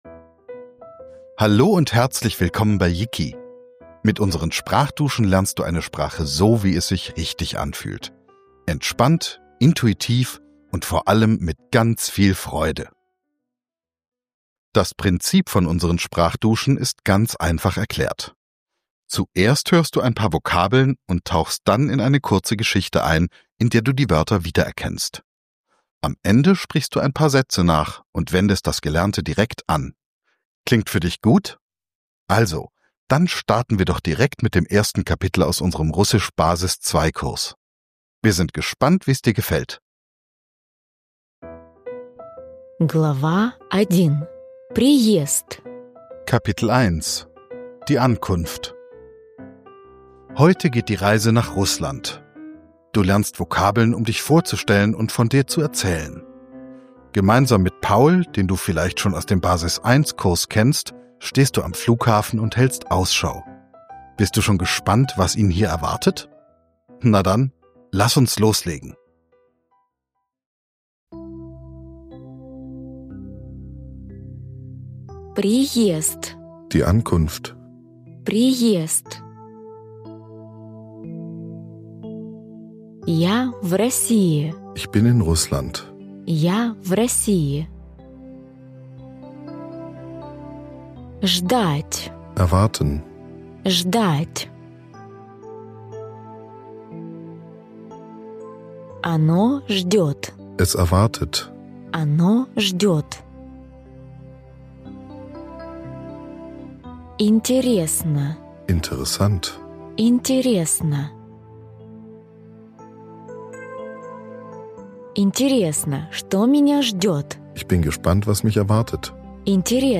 Dich erwarten viele neue Vokabeln, Dialoge und Sätze zum Nachsprechen, um gleichzeitig Russisch sprechen zu lernen.